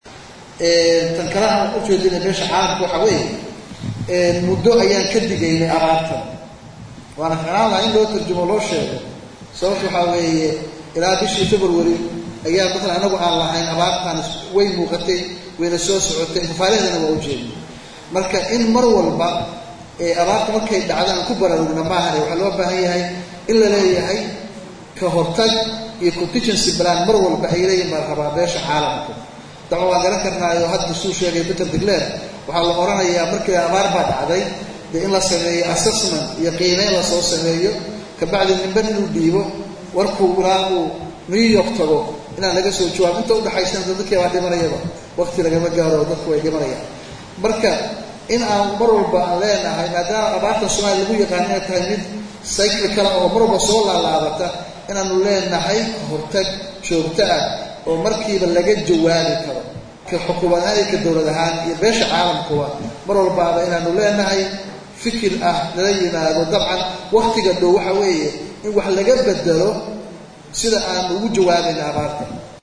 December 21 2016 (Puntlandes)-Madaxweynaha dowladda Puntland Cabdiweli Maxamed Cali Gaas oo warbaahinta kulahadlay magaalada Muqdisho ayaa sheegay in beesha caalamku ka gaabisay gurmadka dadka abaaruhu saameeyeen.